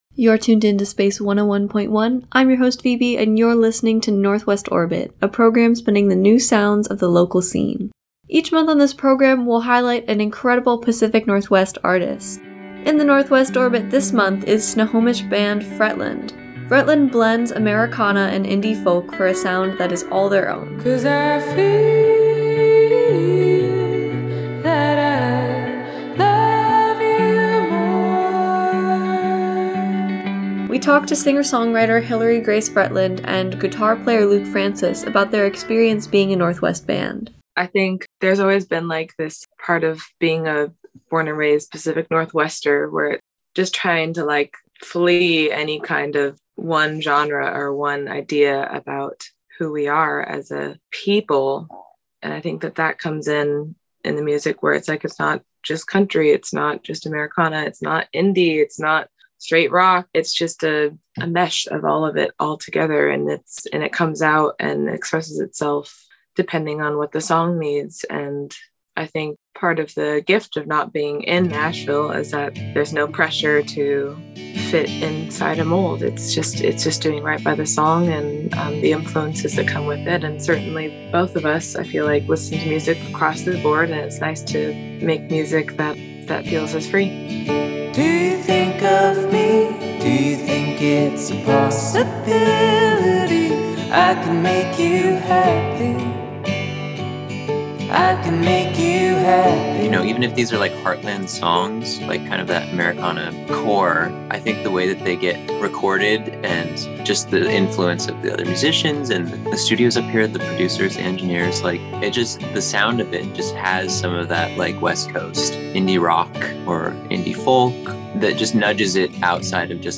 NW Orbit Artist Interviews